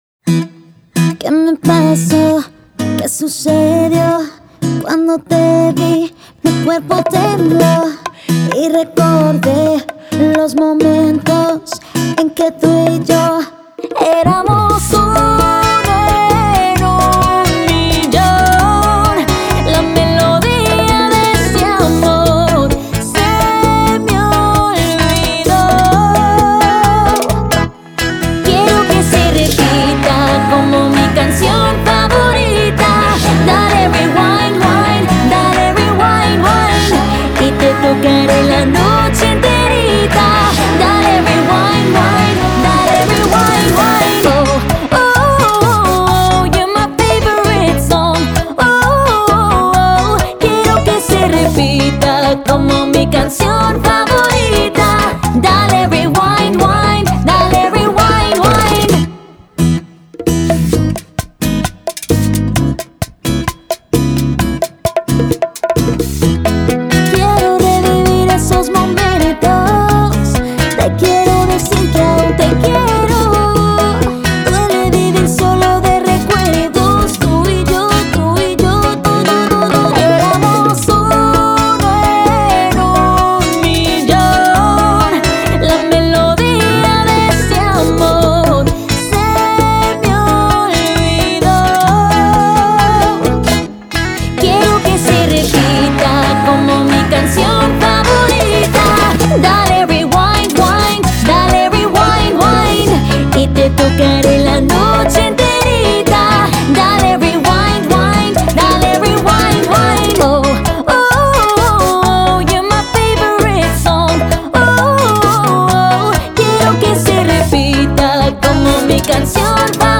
Незатейливая бачата от молодой мексиканской исполнительницы